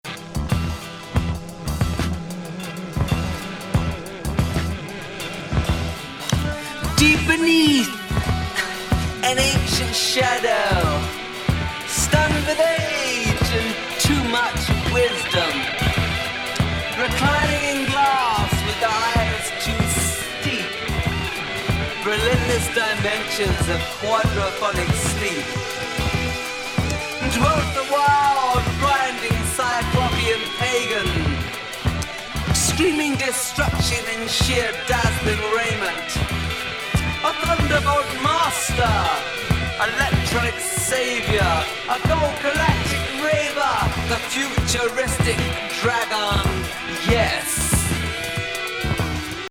コズミック・シンセ・フィーチャー。